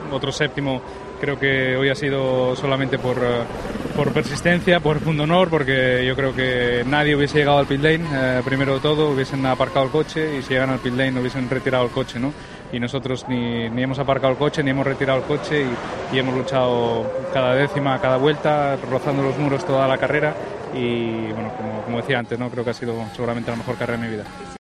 "Carrera muy loca, otro séptimo, que creo que hoy ha sido por persistencia, por pundonor, nadie habría llegado al 'pit lane' con el daño que nosotros teníamos, y ni aparcamos ni hemos retirado el coche, luego hemos luchado cada décima y cada vuelta. Creo que seguramente ha sido la mejor carrera de mi vida" señaló el piloto asturiano ante los medios de comunicación.